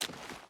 Water Run 4.ogg